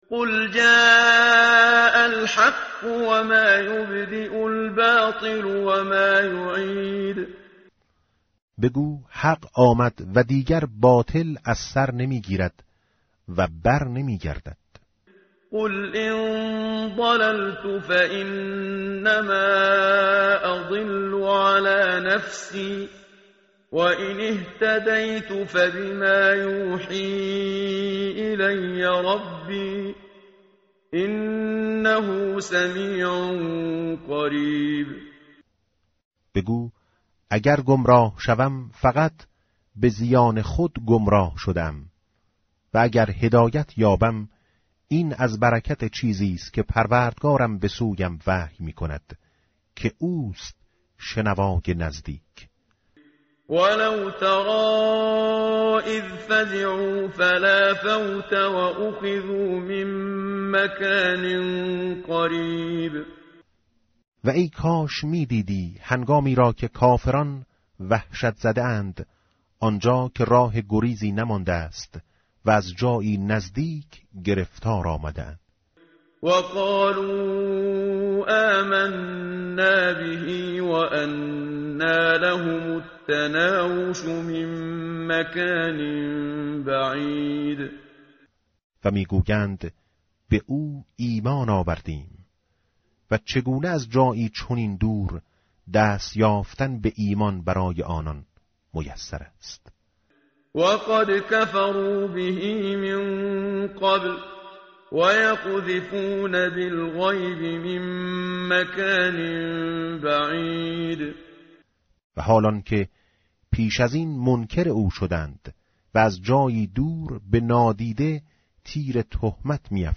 tartil_menshavi va tarjome_Page_434.mp3